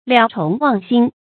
蓼虫忘辛 liǎo chóng wàng xīn
蓼虫忘辛发音